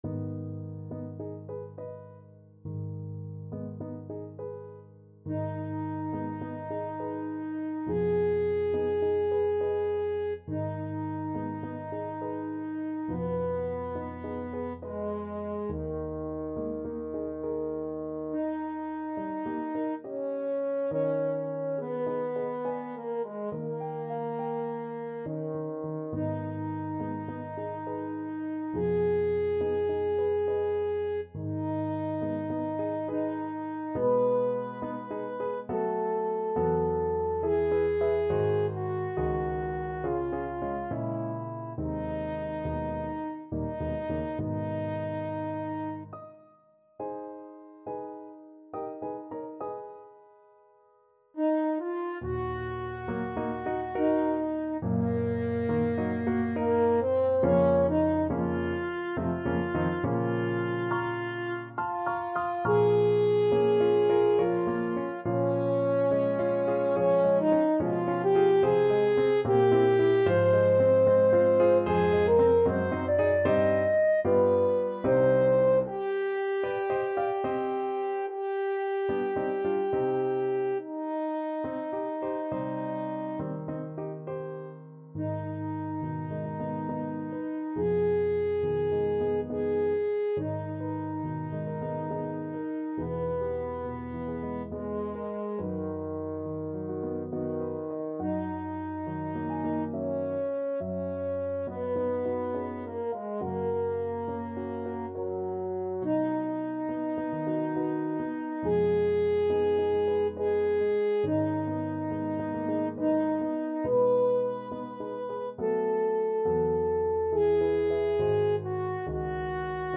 Free Sheet music for French Horn
French Horn
3/8 (View more 3/8 Music)
Ab minor (Sounding Pitch) Eb minor (French Horn in F) (View more Ab minor Music for French Horn )
Andante =69
Eb4-Eb6
Classical (View more Classical French Horn Music)